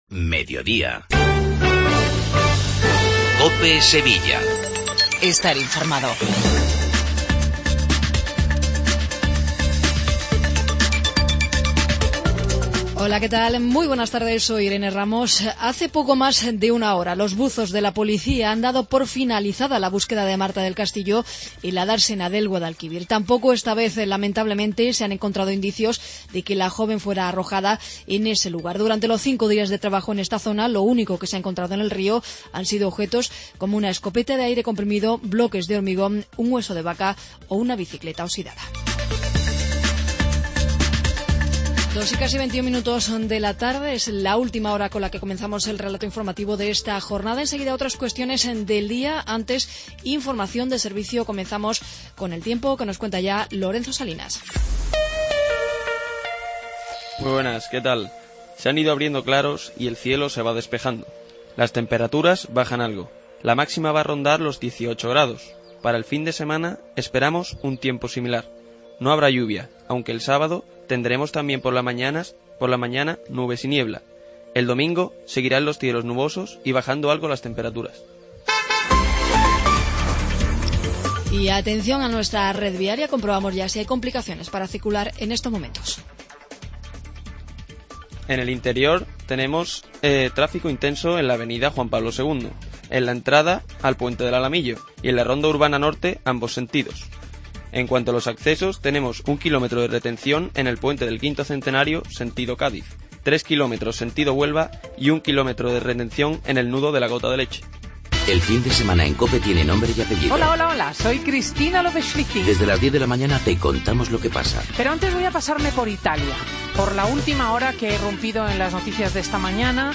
INFORMATIVO LOCAL MEDIODIA COPE SEVILLA